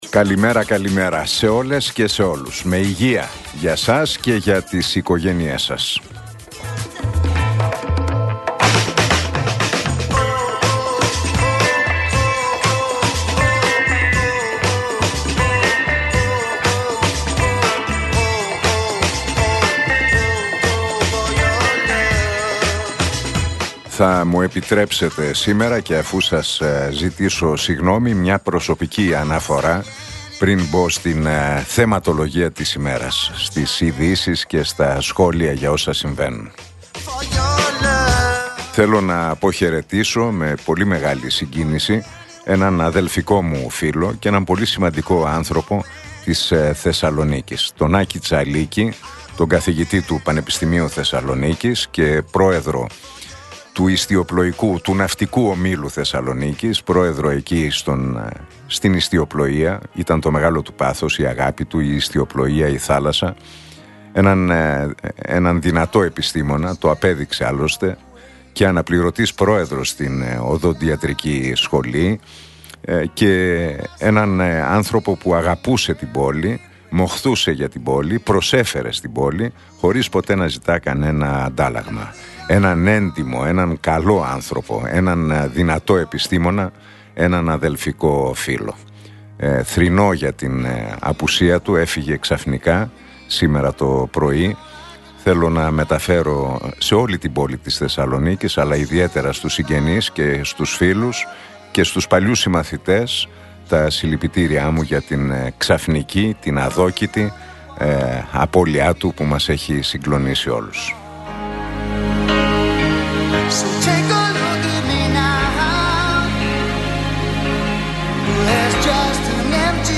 Ακούστε το σχόλιο του Νίκου Χατζηνικολάου στον ραδιοφωνικό σταθμό Realfm 97,8, την Πέμπτη 2 Οκτώβριου 2025.